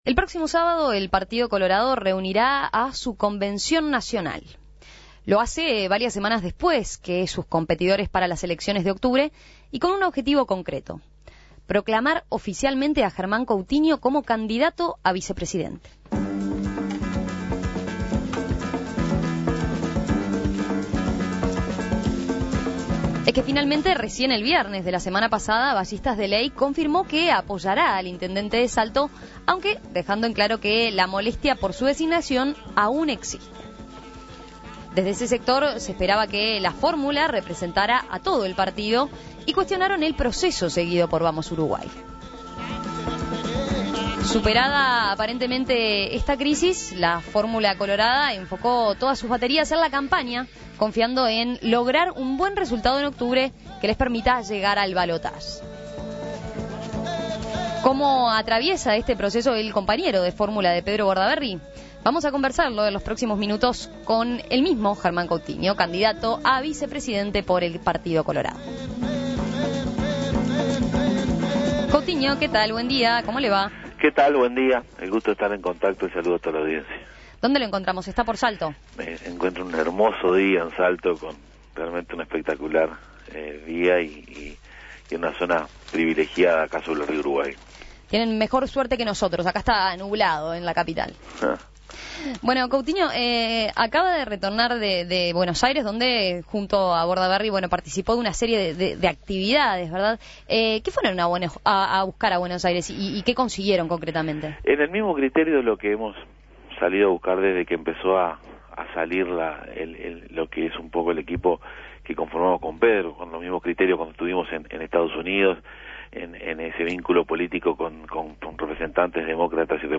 Previo a la proclama En Perspectiva entrevistó al candidato para conocer cómo trabajan junto a Pedro Bordaberry de cara a octubre.